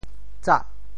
潮州 zah8 白 对应普通话: zhá 把食物放在煮沸的油中弄熟：～酱面 | ～糕 | ～鱼 | 油～。
tsah8.mp3